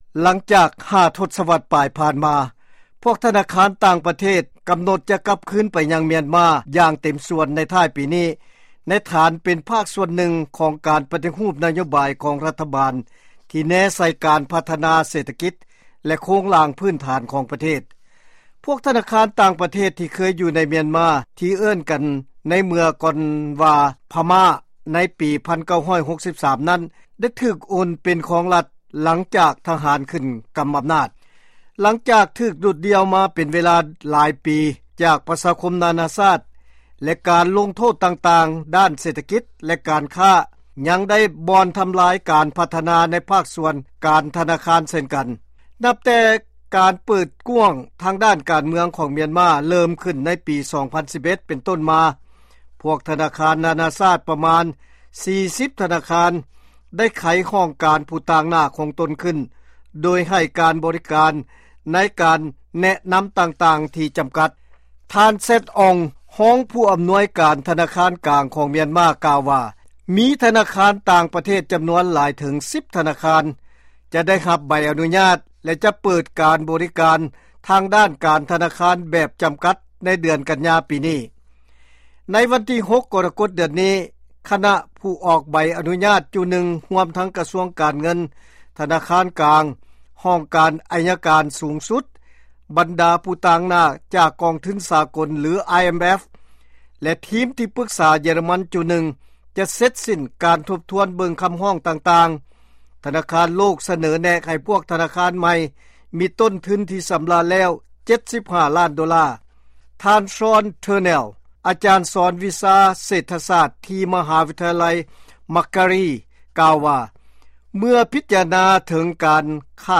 ເຊີນຟັງລາຍງານກ່ຽວກັບ ພວກທະນາຄານຕ່າງປະເທດ ກັບຄືນໄປຍັງມຽນມາ